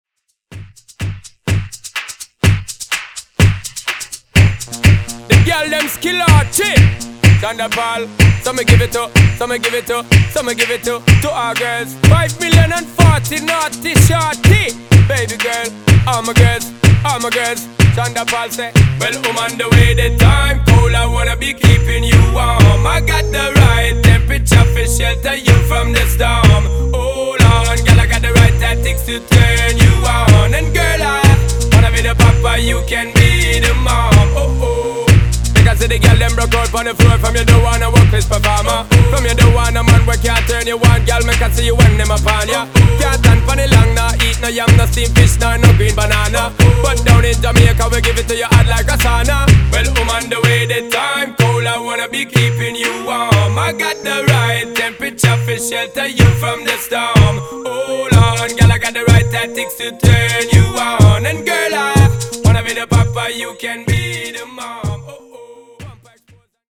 Genres: 90's , RE-DRUM Version: Clean BPM: 128 Time